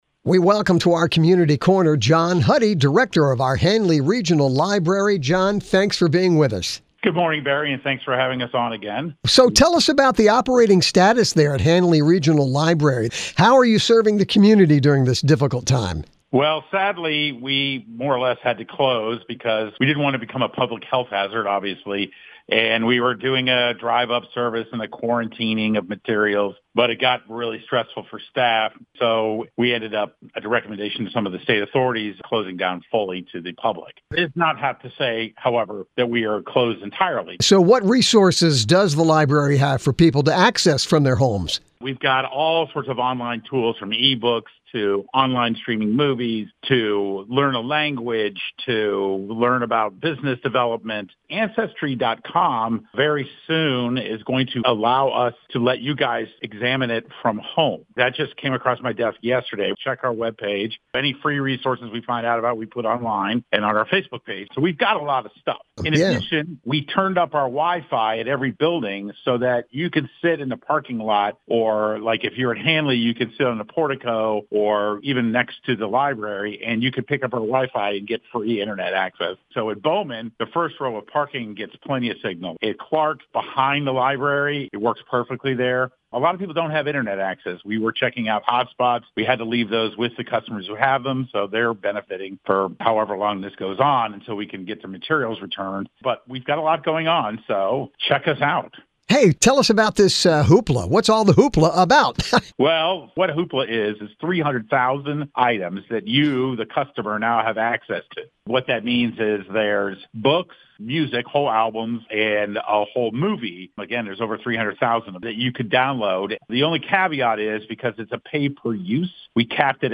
WINC FM Interview